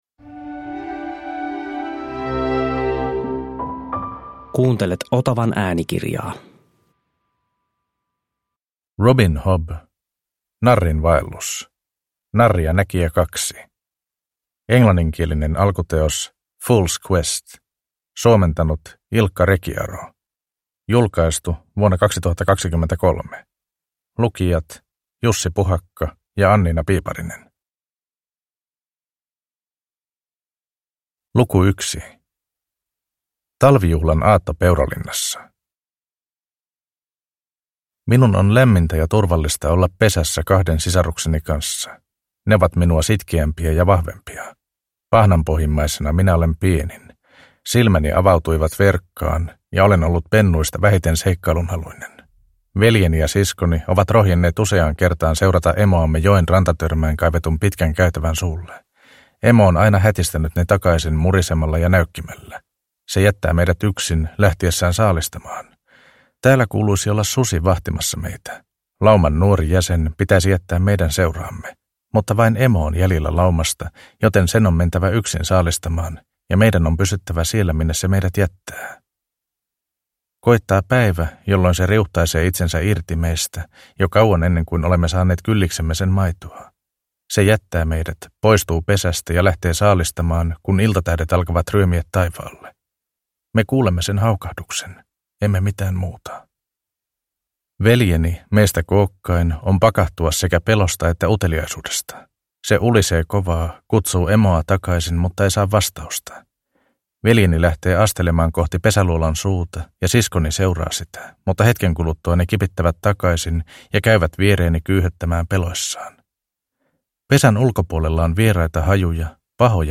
Narrin vaellus – Ljudbok – Laddas ner